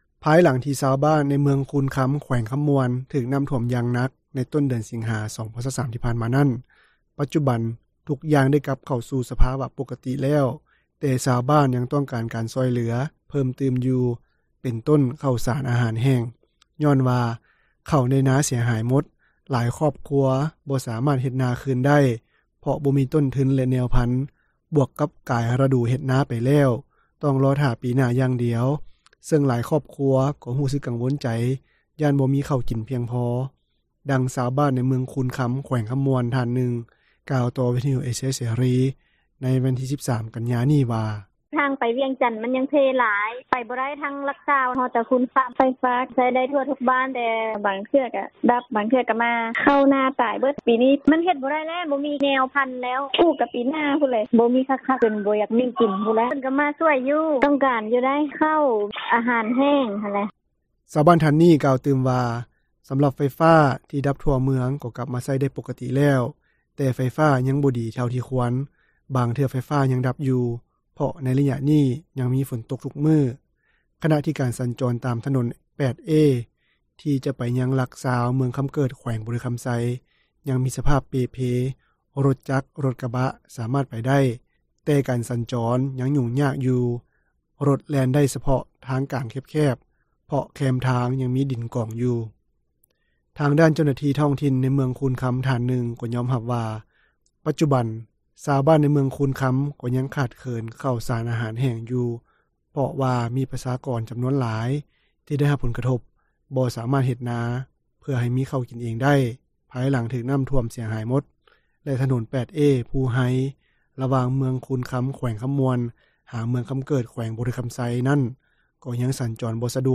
ນັກຂ່າວ ພົລເມືອງ
ດັ່ງຊາວບ້ານ ໃນເມືອງຄູນຄຳ ແຂວງຄຳມ່ວນ ທ່ານນຶ່ງ ກ່າວຕໍ່ວິທຍຸເອເຊັຽເສຣີ ໃນວັນທີ 13 ກັນຍາ ນີ້ວ່າ: